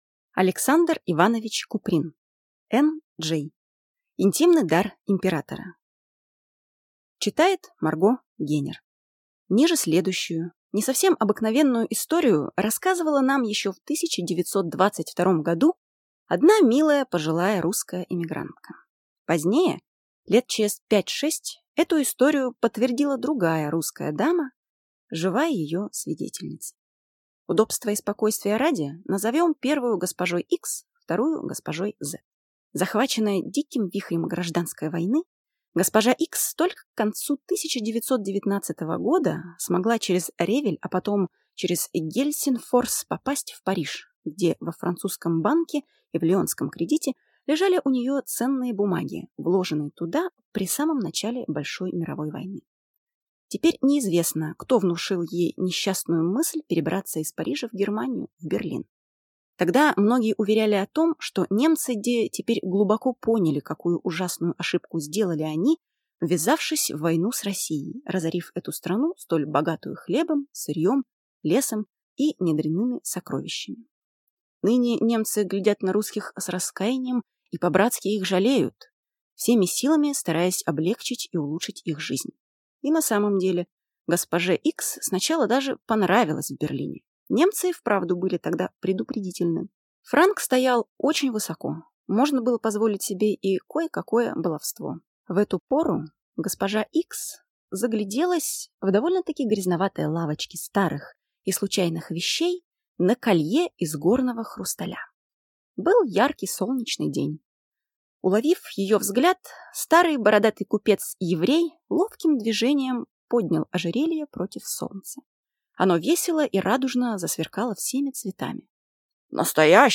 Аудиокнига «N.-J.» Интимный дар императора | Библиотека аудиокниг